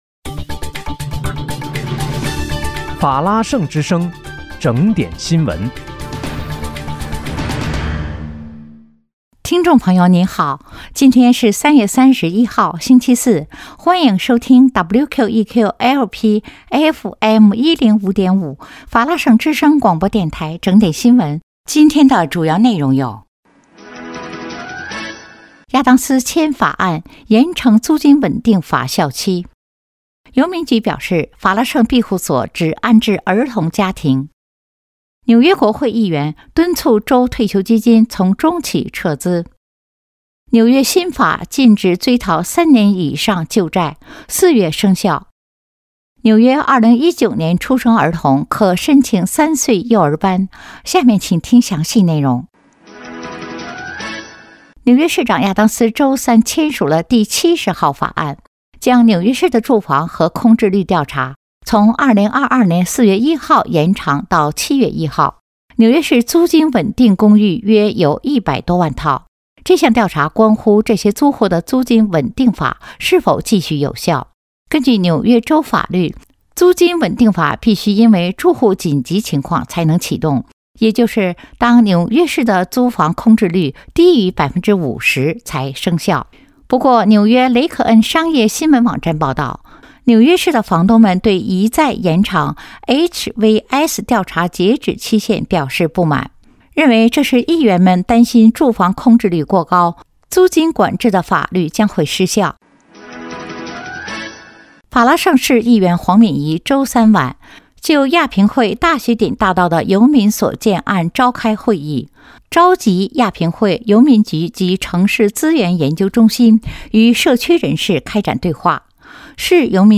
3月31日（星期四）纽约整点新闻